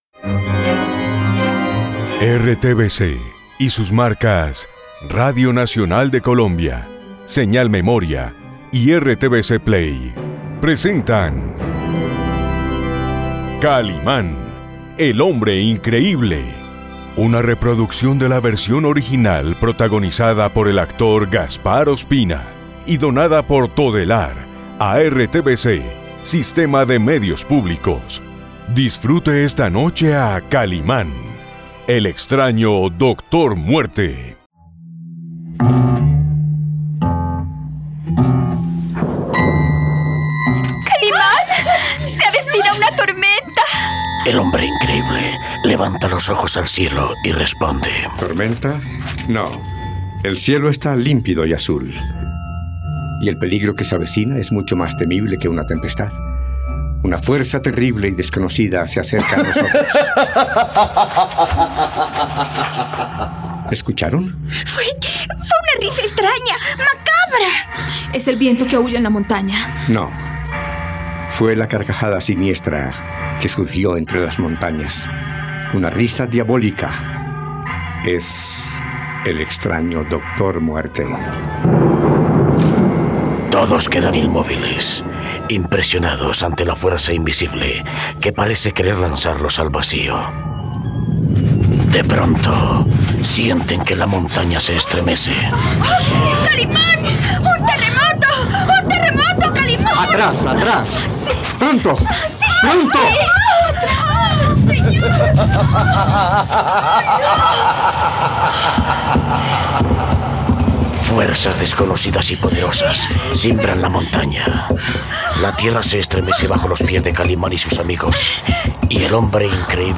..Radionovela. El suelo tiembla y una risa malvada, que suena como la del Dr. Muerte, invade el aire.